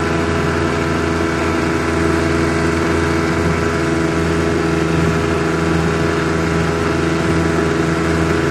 20 hp Johnson Boat Drive Loop High Speed, On Board